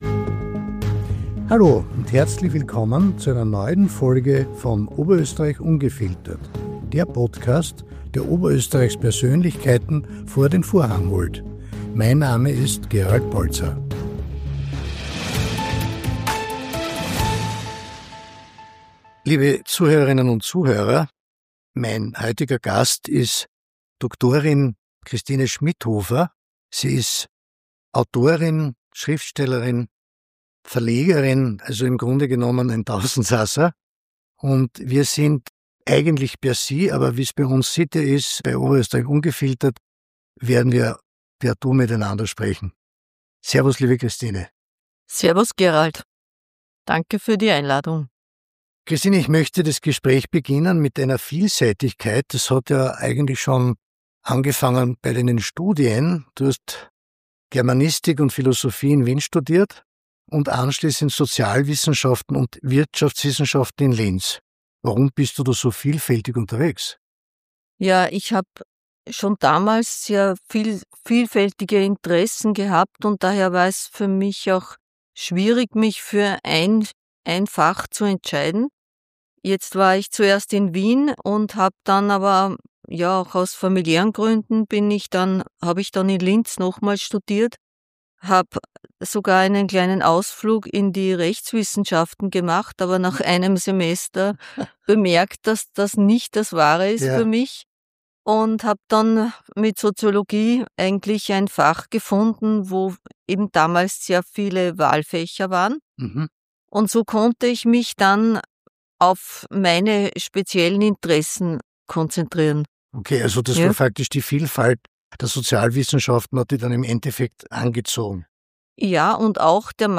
Entdecke die Antworten und lasse dich von dem offenen und ehrlichen Gespräch inspirieren.